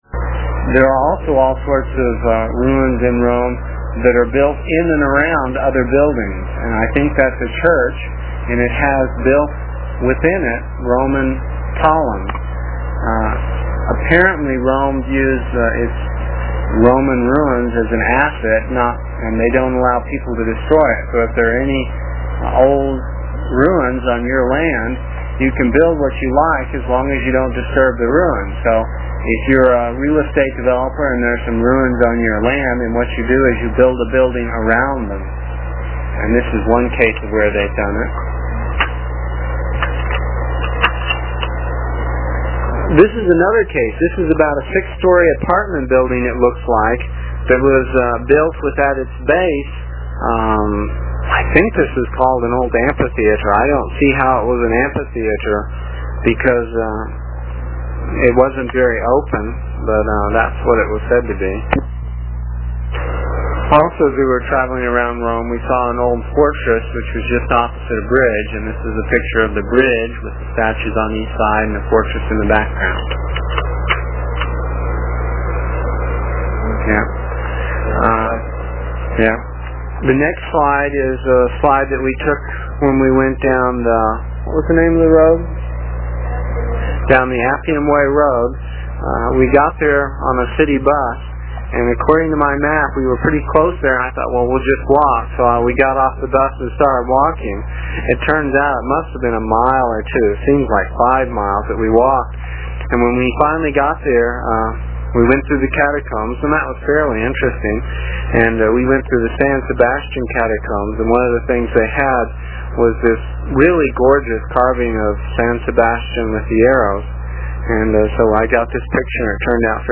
It is from the cassette tapes we made almost thirty years ago. I was pretty long winded (no rehearsals or editting and tapes were cheap) and the section for this page is about four minutes and will take about a minute to download with a dial up connection.